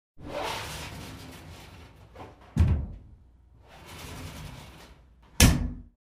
Звуки душа
На этой странице собраны разнообразные звуки душа: от мягкого потока воды до интенсивного массажного режима.